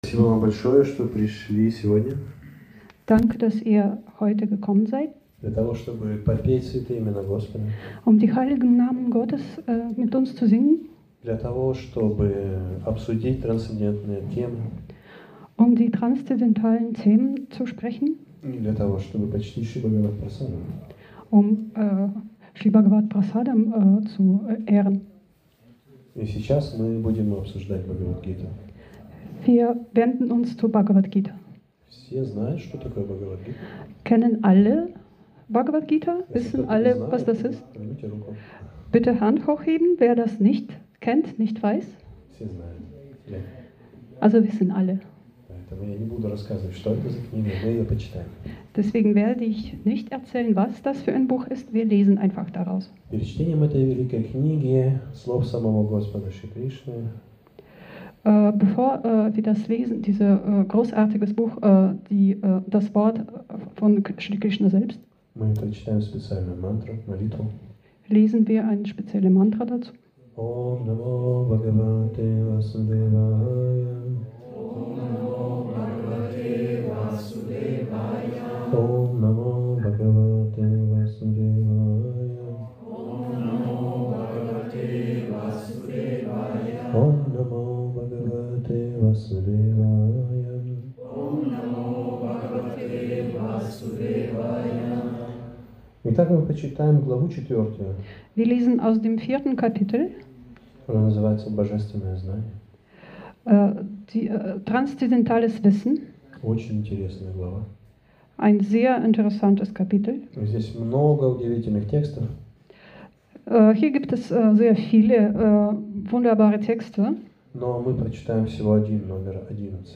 Spiritueller Vortrag
Sonntagsfest 30. März 2025 im Bhakti Yoga Zentrum Hamburg